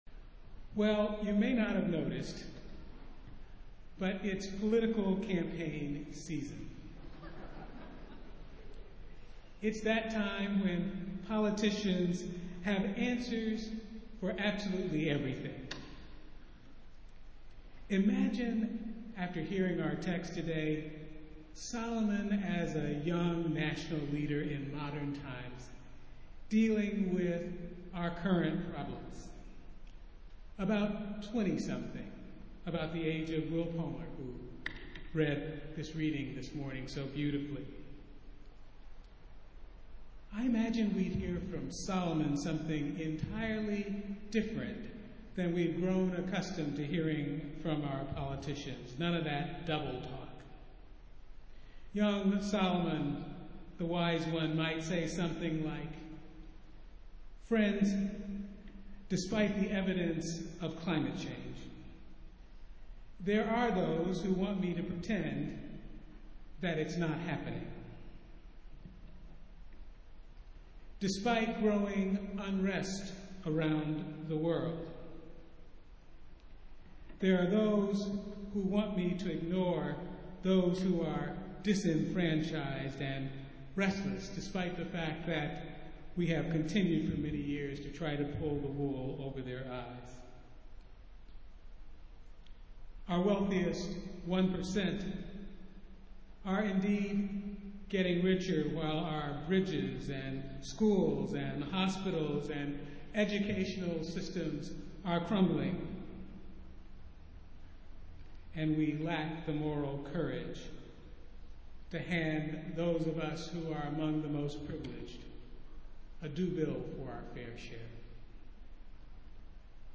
Festival Worship - Twelfth Sunday after Pentecost